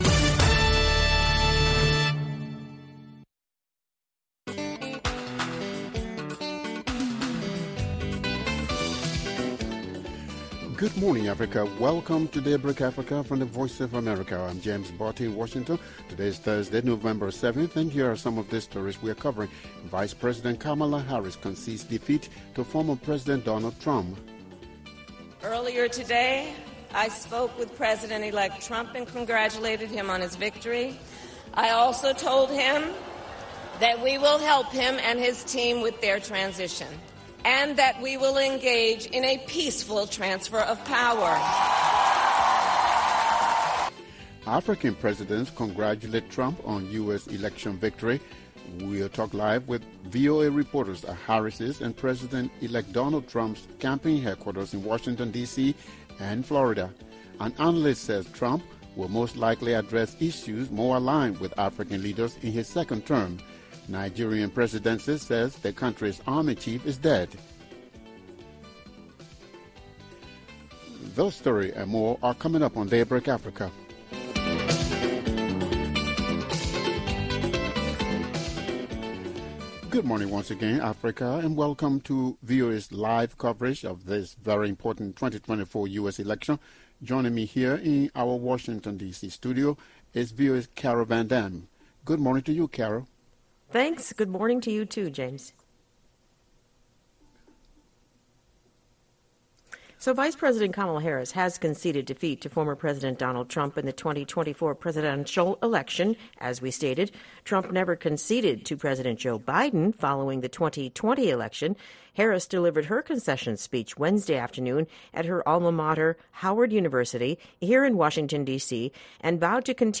We’ll talk live with VOA reporters at the campaign headquarters of Harris and President-elect Donald Trump in Washington, DC and Florida. An analyst says Trump will most likely address issues more aligned with African leaders in his second term.